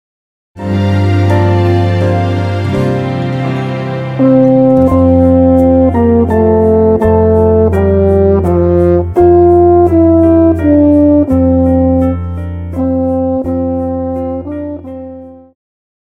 Pop,Christian
French Horn
Band
Christmas Carols,Hymn,POP
Instrumental
Only backing